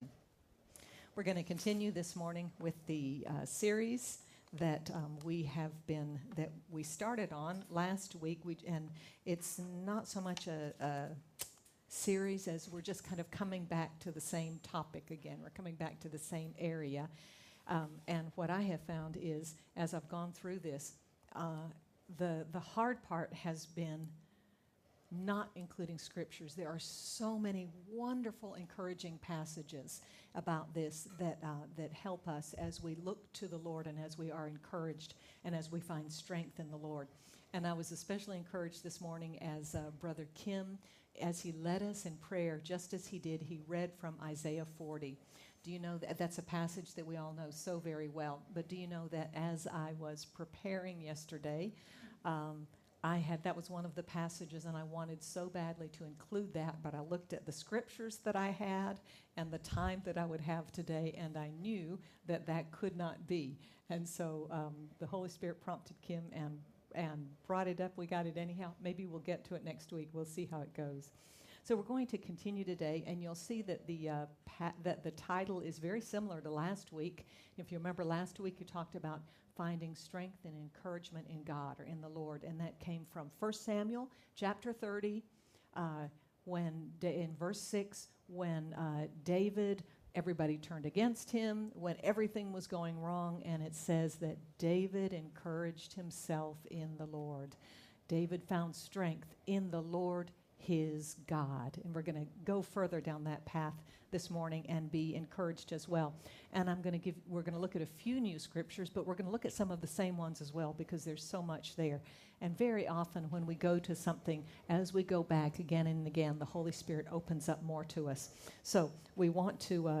Jan 23, 2022 Strength and Encouragement from God MP3 SUBSCRIBE on iTunes(Podcast) Notes Discussion Sermons in this Series Find strength in God—as we read, recall, and rejoice! Sermon by